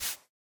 brushing_generic4.ogg